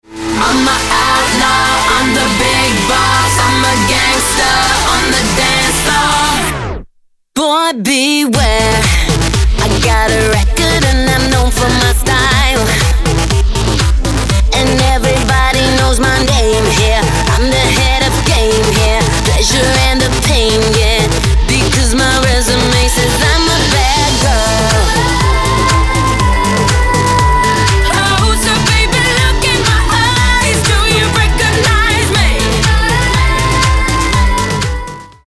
5-členná britská dievčenská popová skupina